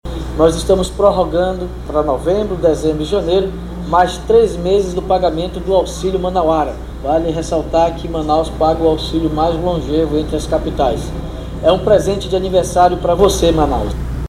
Sonora-David-Almeida-_-prefeito-de-Manaus.mp3